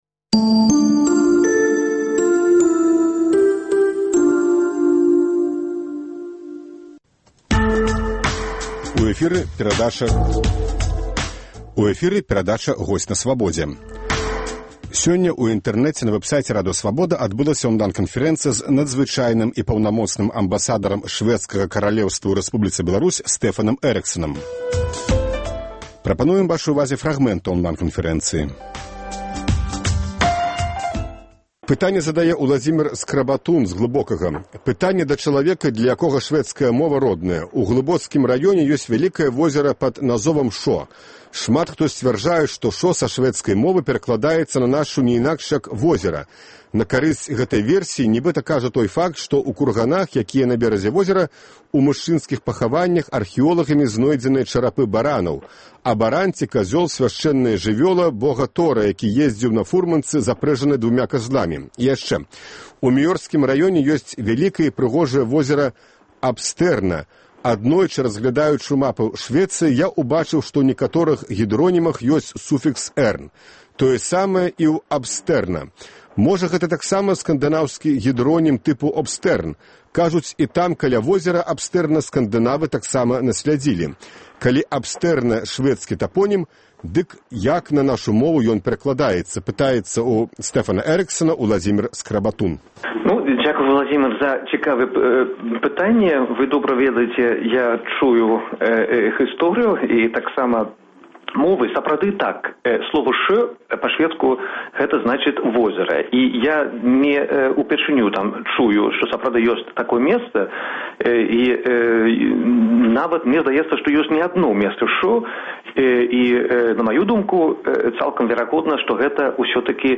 Онлайн- канфэрэнцыя
Запіс онлайн-канфэрэнцыі з амбасадарам Швэдзкага каралеўства Стэфанам Эрыксанам.